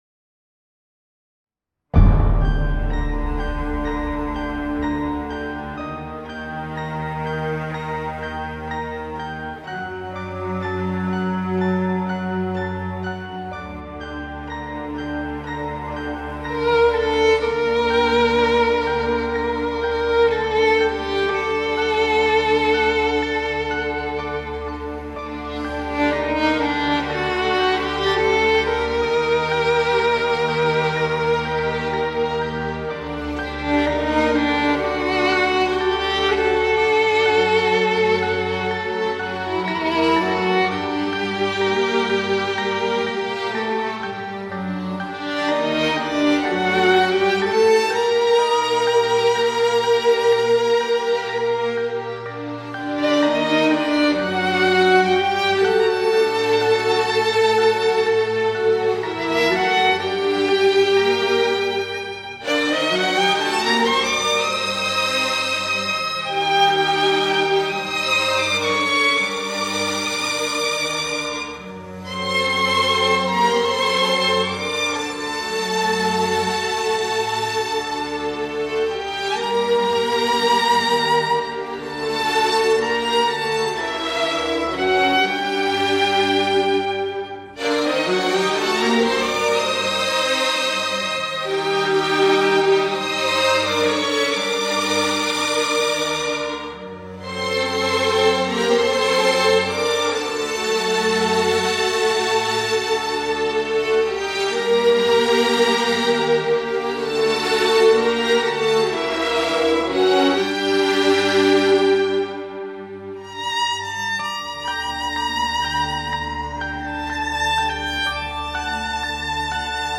ویولن
به صورت بی‌کلام
ویلنسل